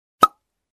Звуки пробки бутылки
Звук открывающейся пробки